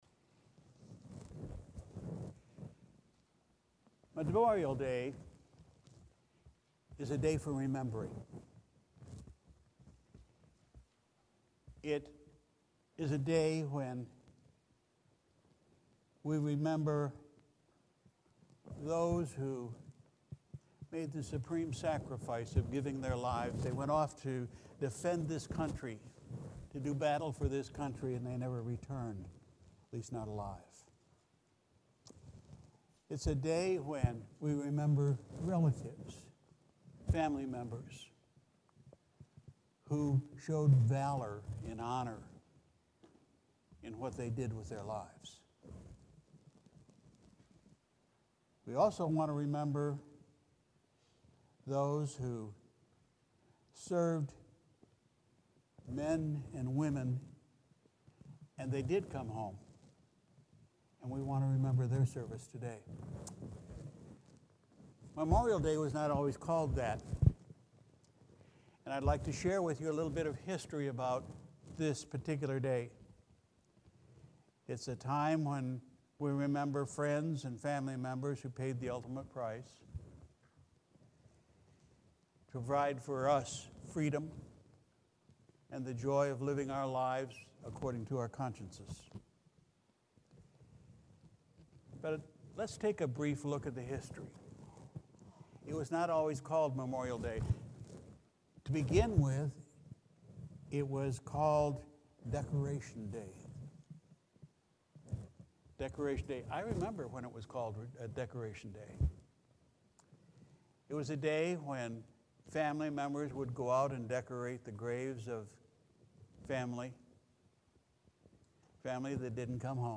Sunday, May 24, 2020 – Memorial Day – Morning Service